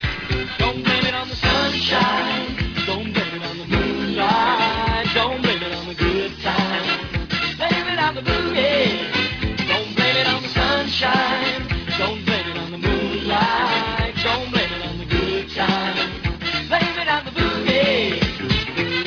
"Blue eyes Soul."